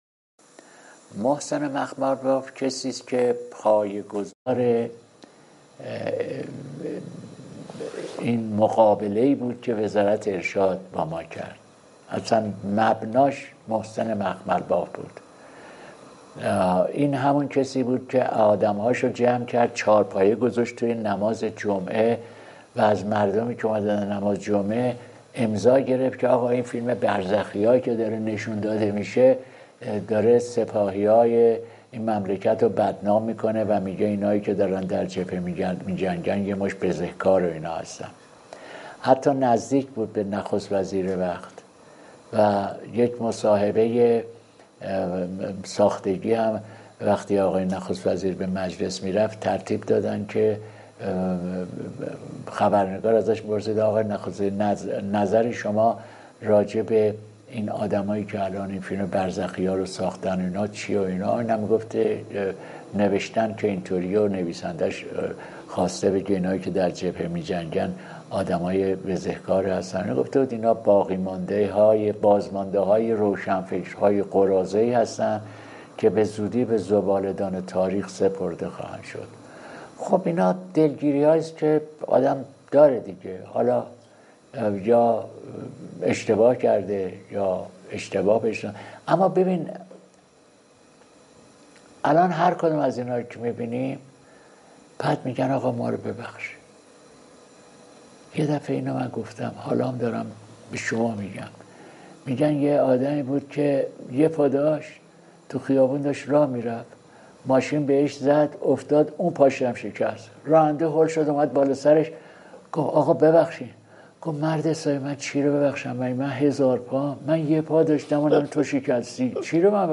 گفتگو با شرق